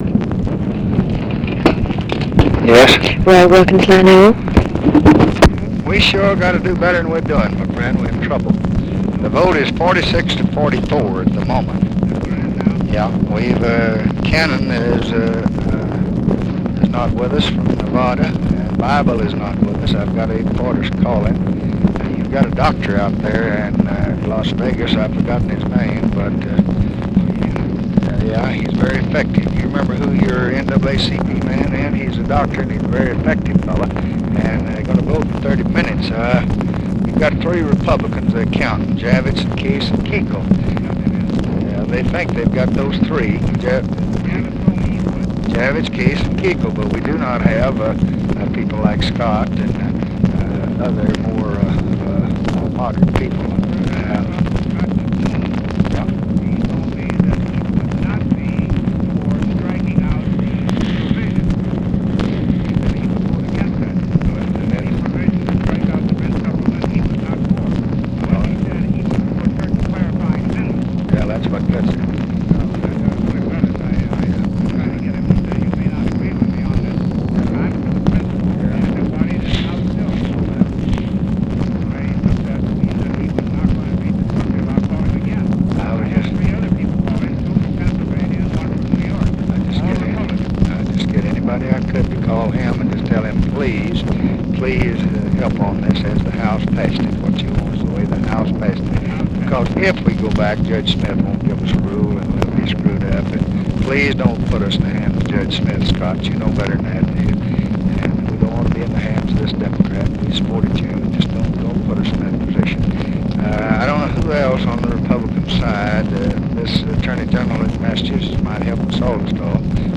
Conversation with ROY WILKINS, July 15, 1965
Secret White House Tapes